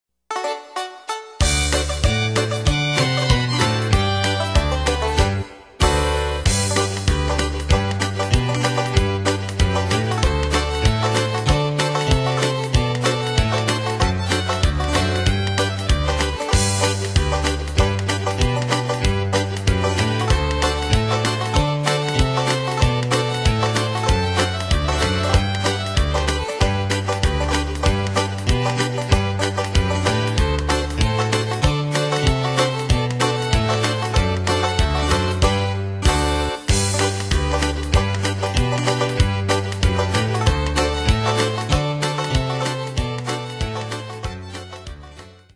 Style: Country Année/Year: 1976 Tempo: 190 Durée/Time: 2.47
Pro Backing Tracks